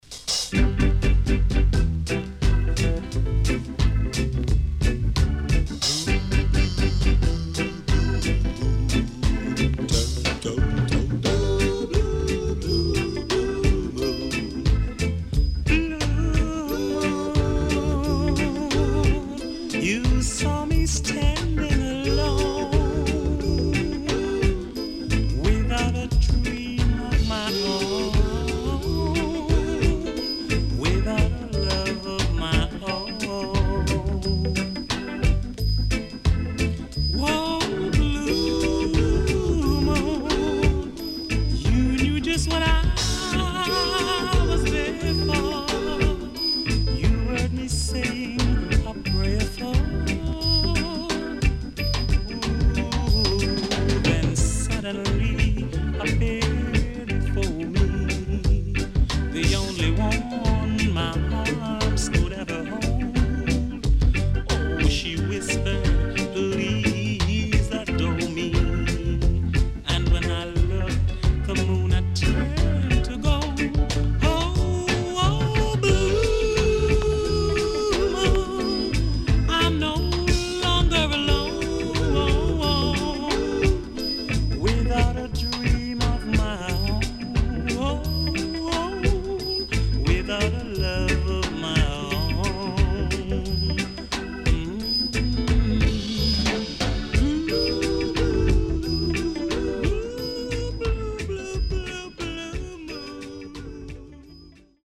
HOME > REGGAE / ROOTS  >  EARLY REGGAE
CONDITION SIDE A:VG(OK)
W-Side Nice Vocal
SIDE A:序盤小傷により周期的にプチノイズ入ります。所々チリノイズが入ります。